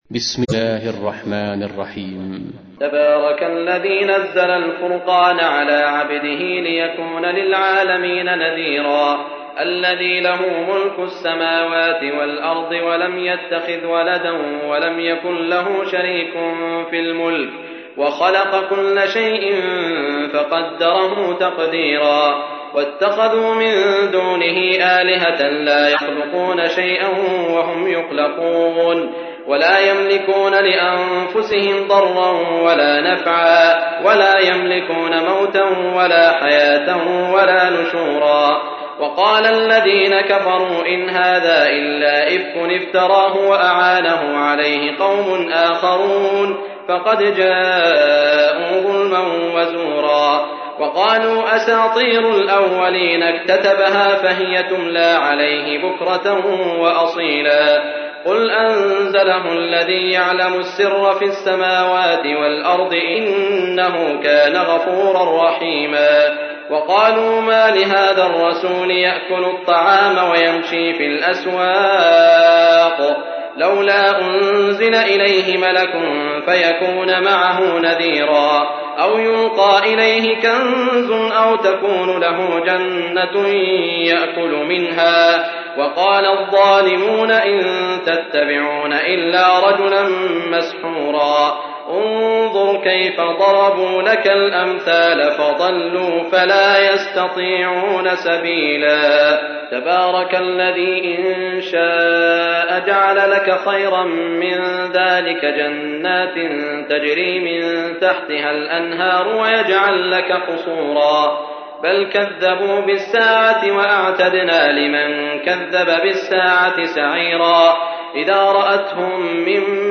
تحميل : 25. سورة الفرقان / القارئ سعود الشريم / القرآن الكريم / موقع يا حسين